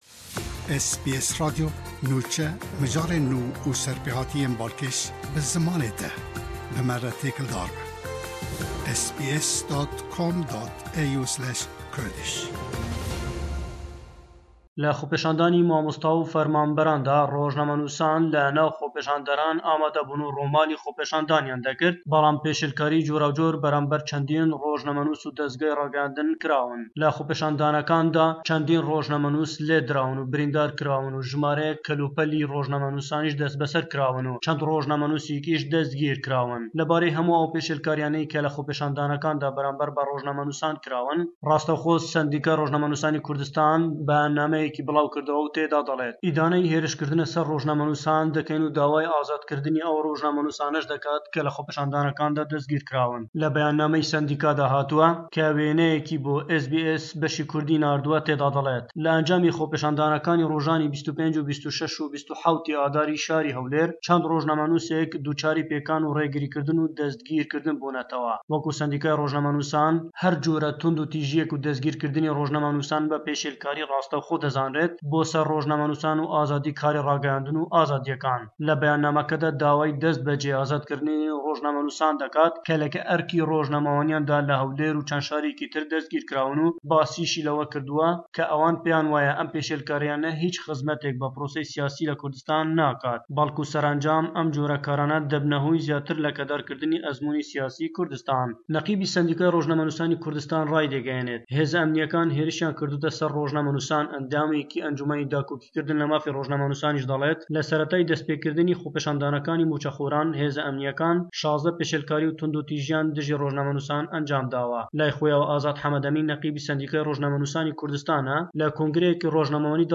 Raporta